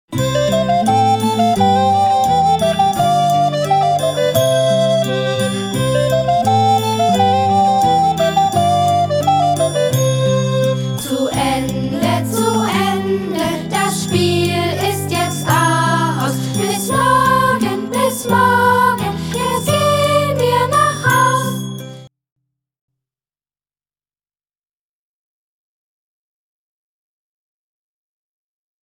Gattung: Sing- und Sprechkanons für jede Gelegenheit
Besetzung: Gesang Noten